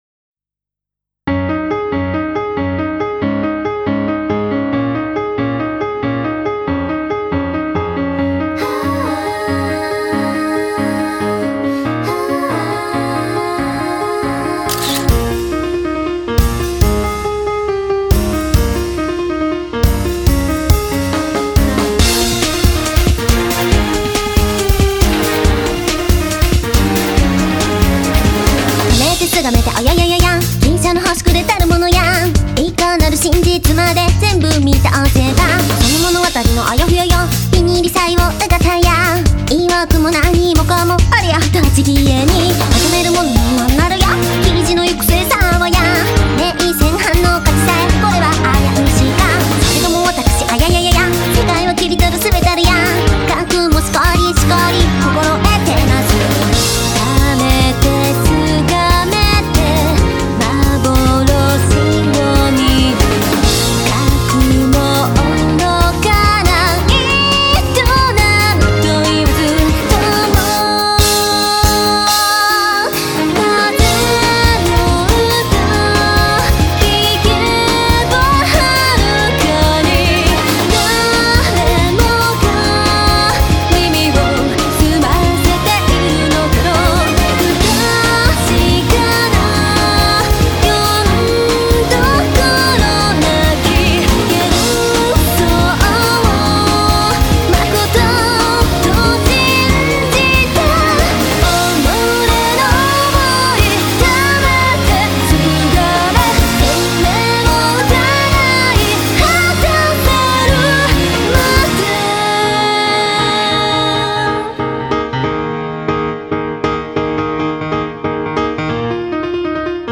東方マルチジャンルアレンジアルバムです。
ロック、ポップス中心に、軽めの感じで！